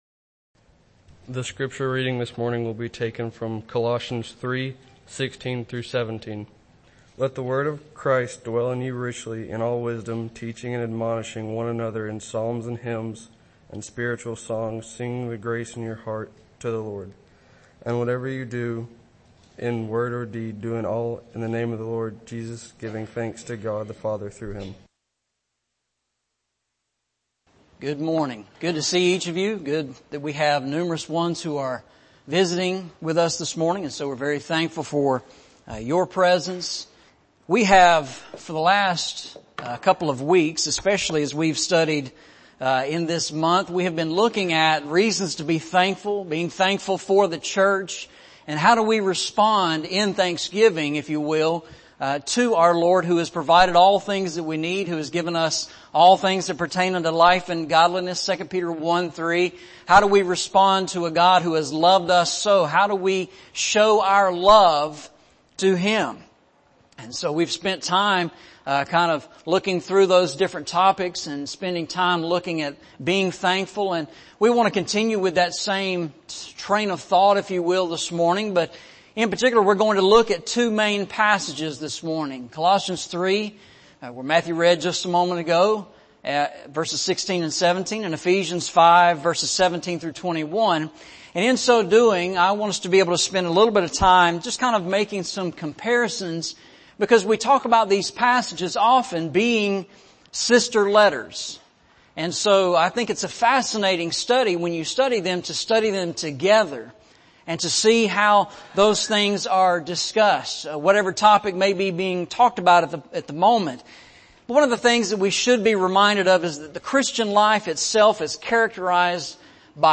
Series: Eastside Sermons
Eastside Sermons Service Type: Sunday Morning Preacher